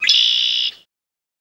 Blackbird, Redwing Call